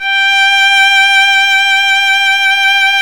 Index of /90_sSampleCDs/Roland - String Master Series/STR_Violin 2&3vb/STR_Vln2 % + dyn